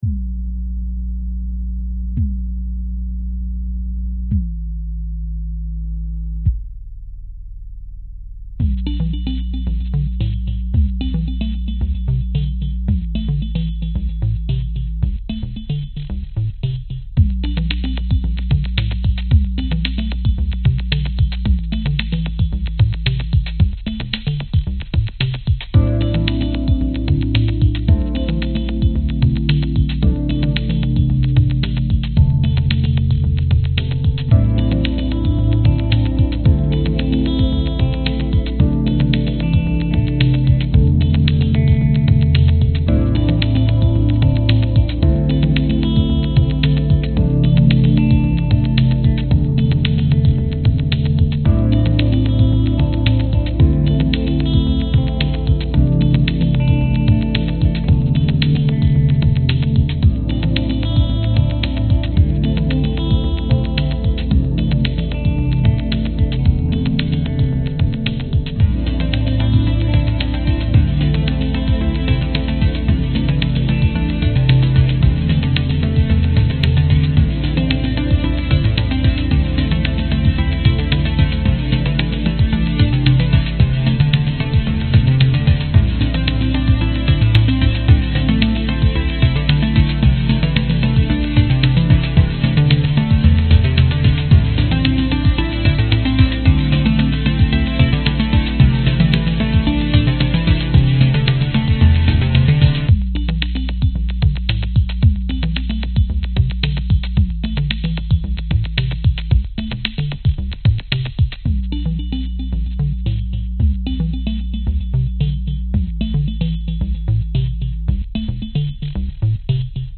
描述：现场吉他和软合成器的旋律。
Tag: 吉他 合成器 循环 冷酷 器乐 电影音乐 视频音乐